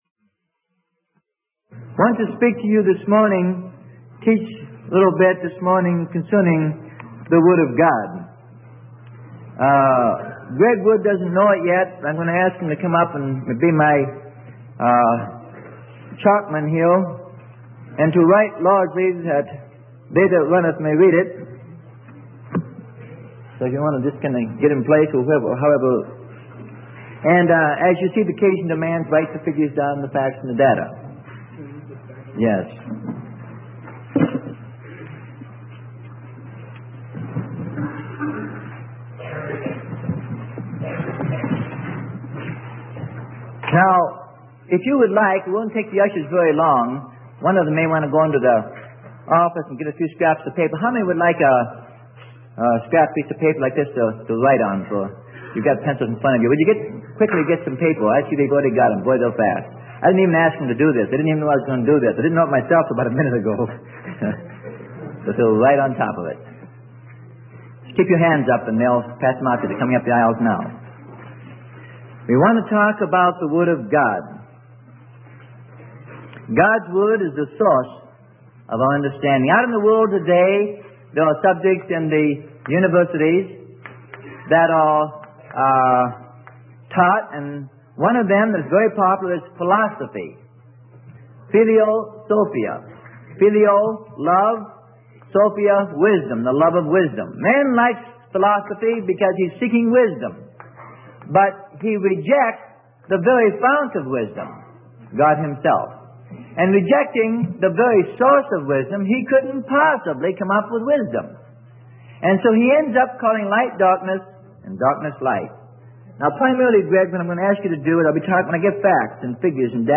Sermon: The Word of God - Part 1 - A Talk on the Bible - Freely Given Online Library